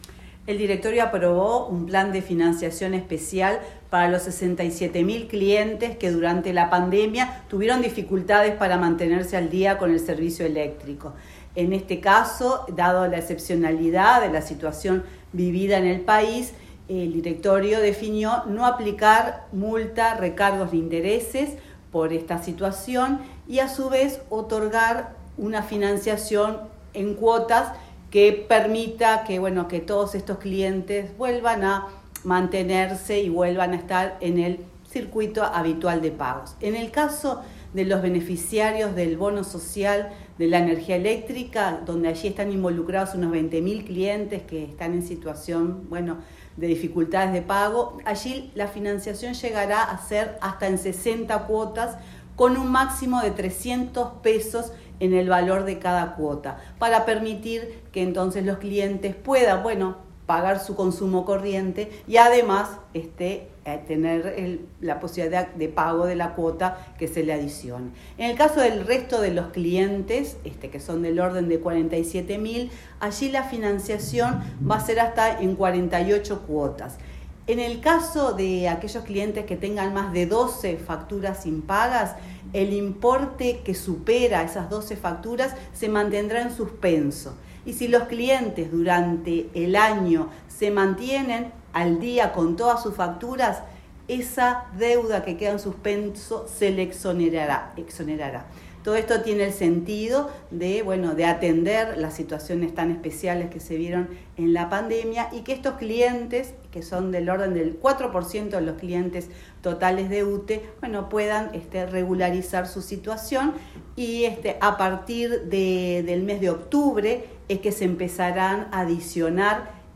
Entrevista a la presidenta de UTE